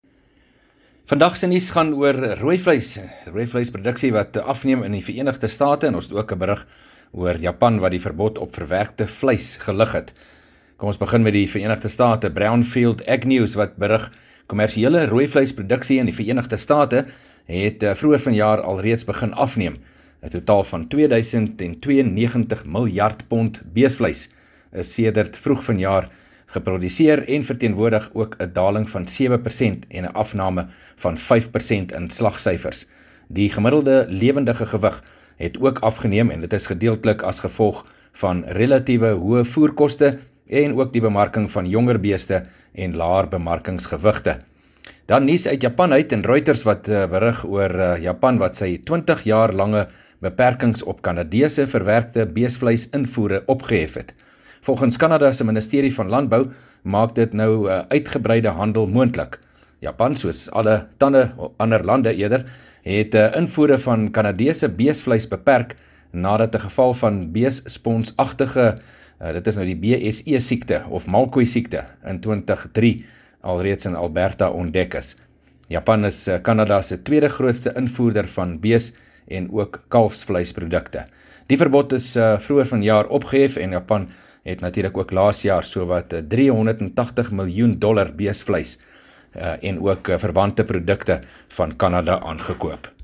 31 May PM berig oor japan wat hul verbod op verwerkte vleis na 20 jaar opgehef het